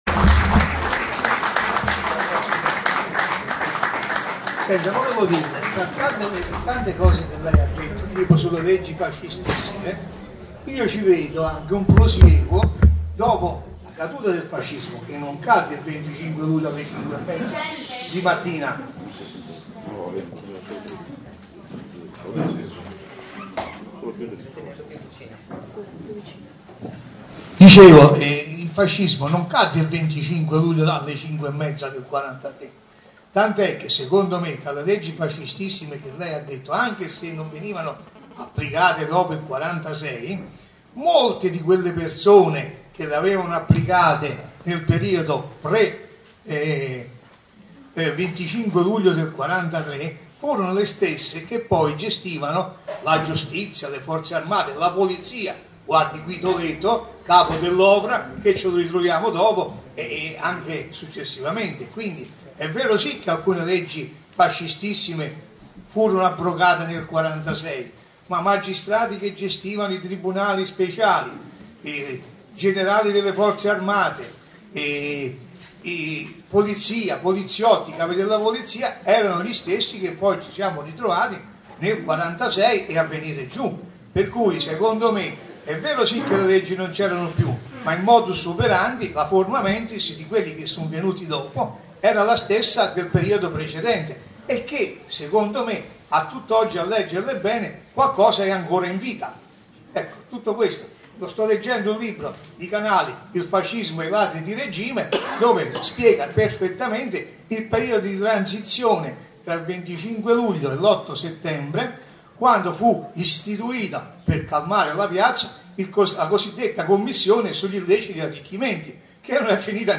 Una conferenza su come si costruisce una dittatura